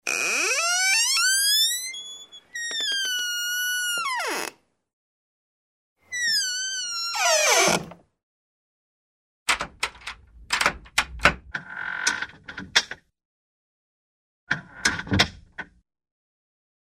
Звуки скрипов
Скрип тяжелой деревянной двери — второй вариант